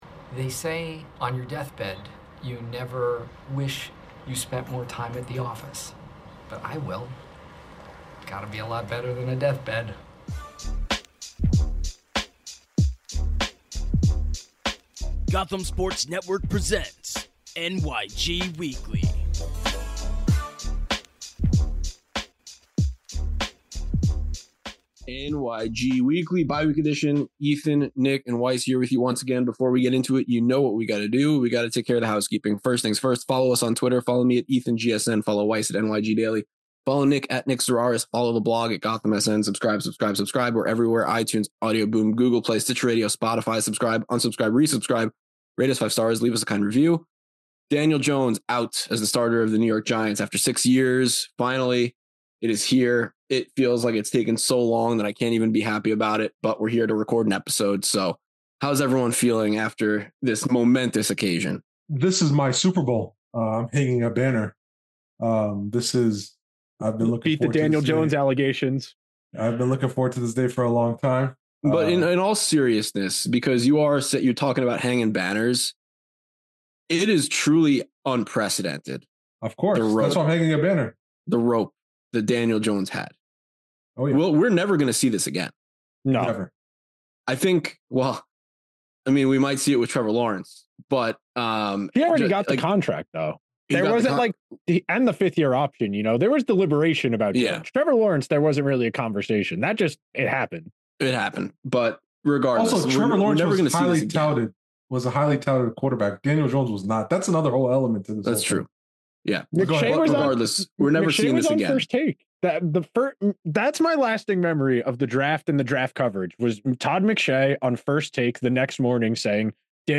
a wide ranging conversation